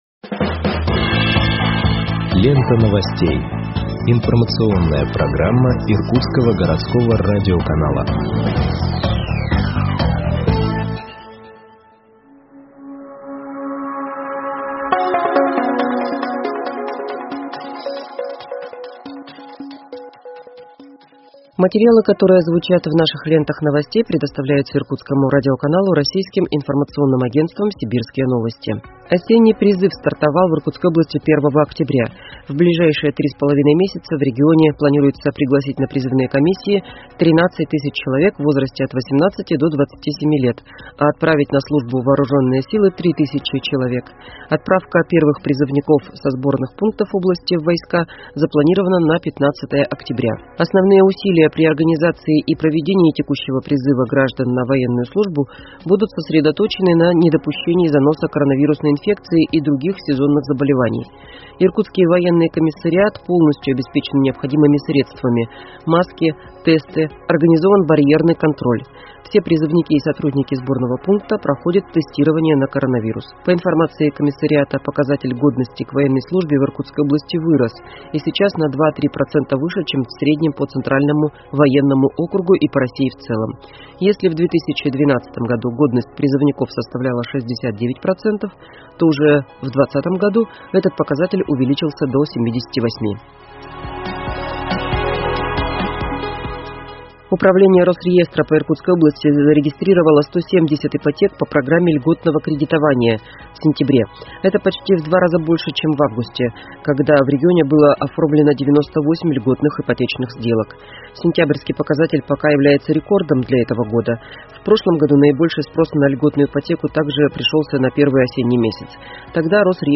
Выпуск новостей в подкастах газеты Иркутск от 11.10.2021 № 1